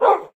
wolf_bark1.ogg